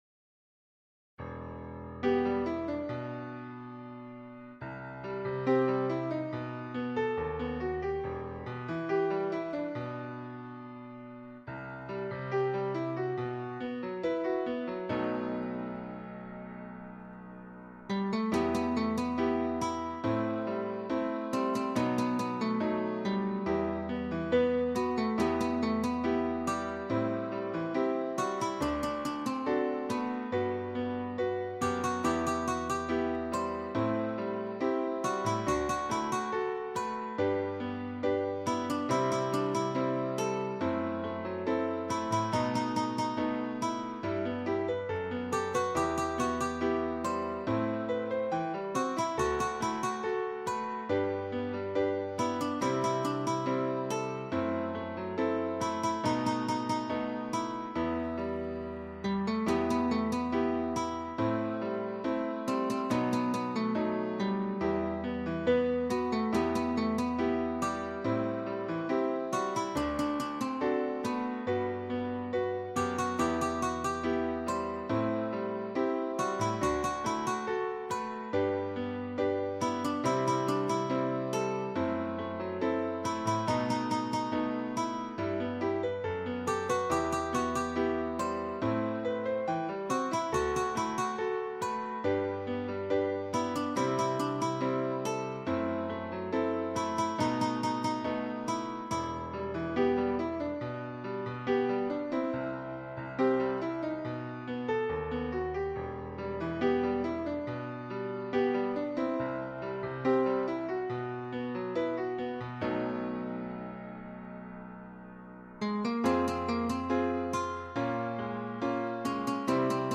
• Теги: Ноты для фортепиано
Ноты для фортепиано, соло гитара